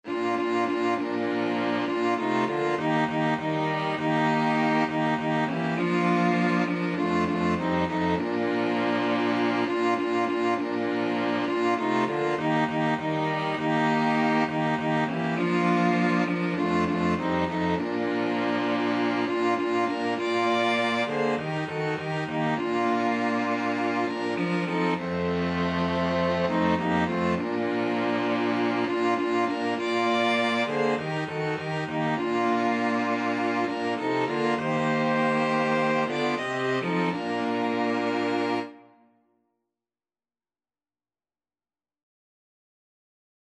Christian
Violin 1Violin 2ViolaCello
4/4 (View more 4/4 Music)
String Quartet  (View more Easy String Quartet Music)